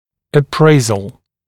[ə’preɪzl][э’прэйзл]оценка (например, степени какого-либо явления и т.п.)